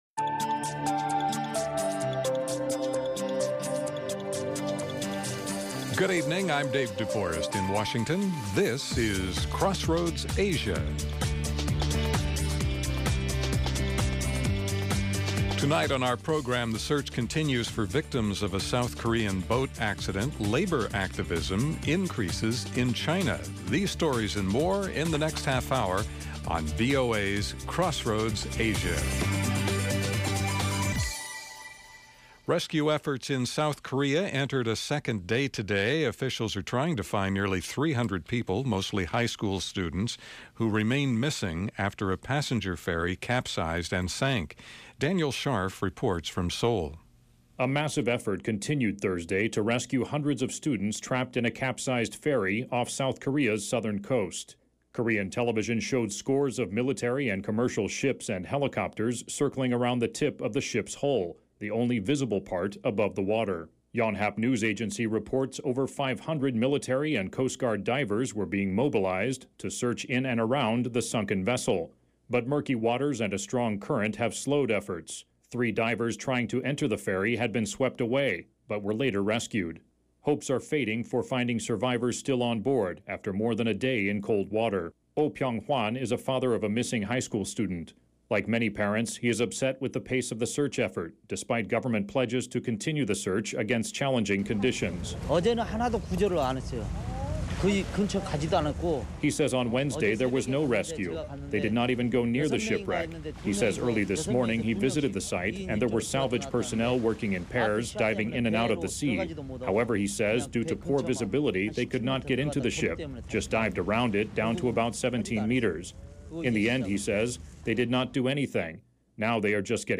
Crossroads Asia offers unique stories and perspectives -- with in-depth interviews, and analysis.